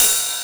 VEC3 Ride
VEC3 Cymbals Ride 06.wav